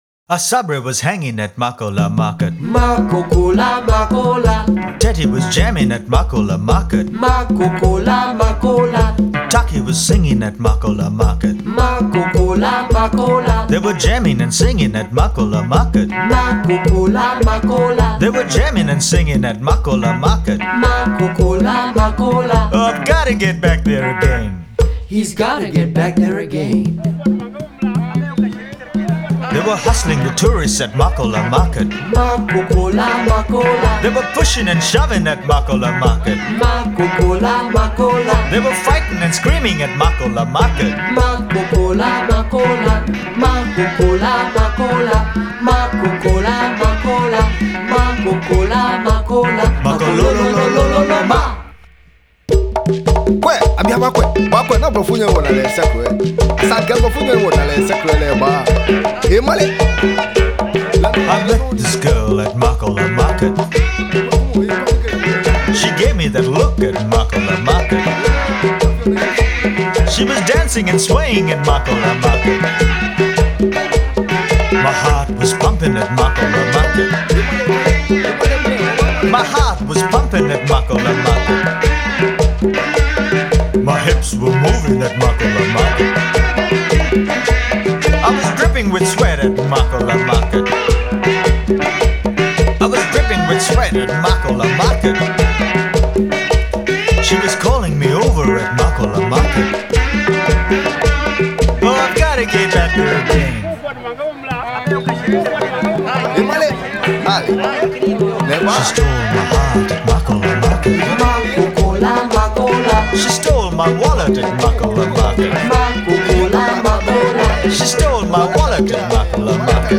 kalimba
sax, bass and balafon.